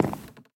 wood4.mp3